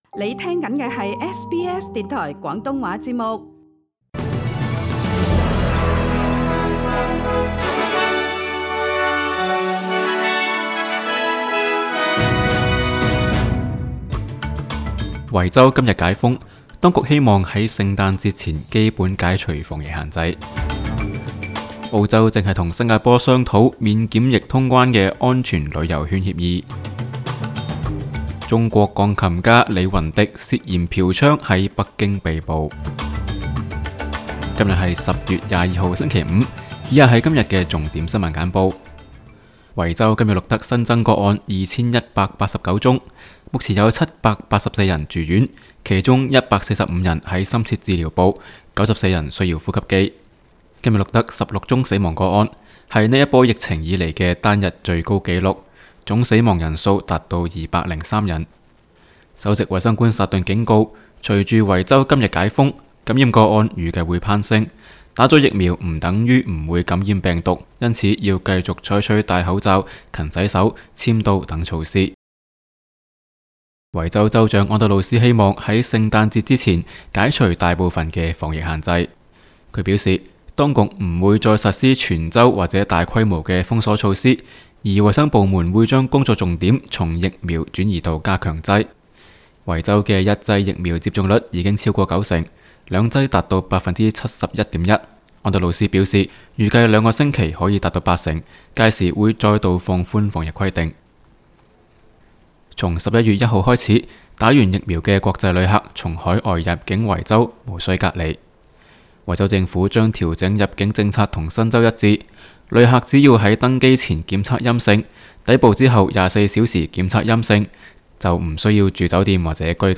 SBS 新聞簡報（10月22日）
SBS 廣東話節目新聞簡報 Source: SBS Cantonese